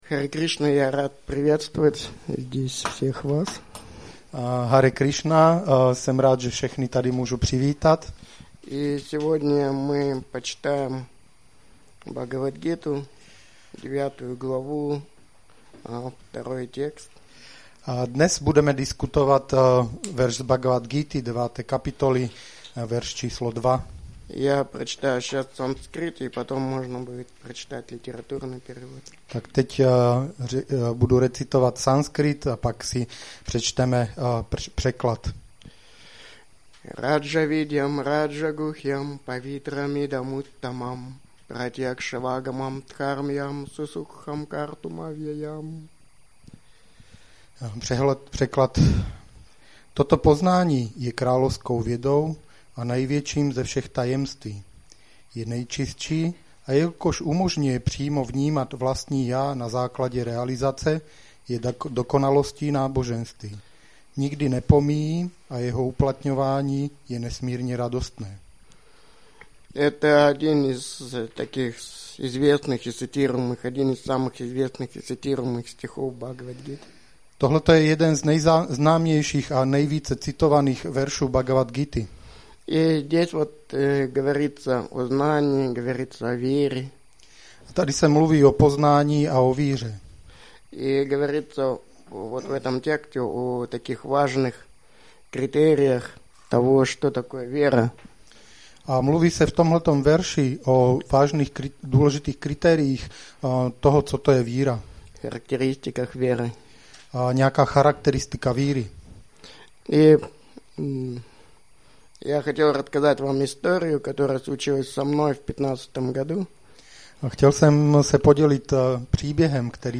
Přednáška BG-9.2